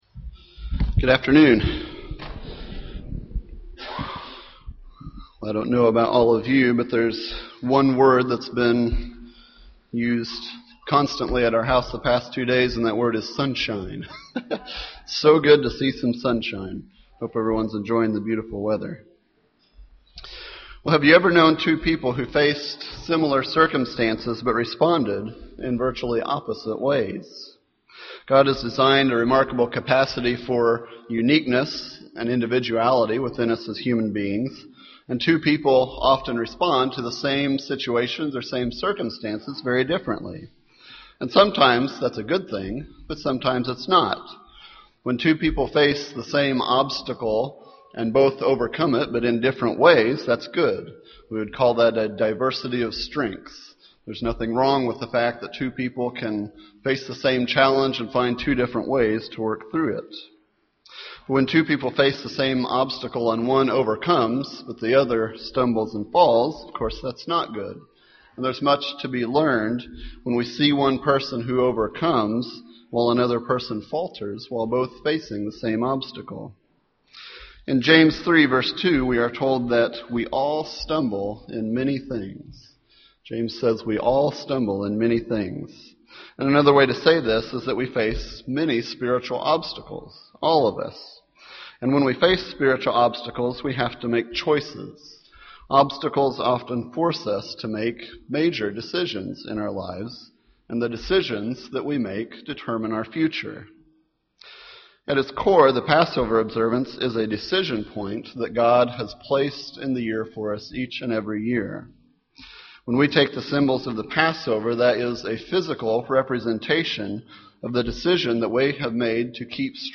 Given in Indianapolis, IN
UCG Sermon Studying the bible?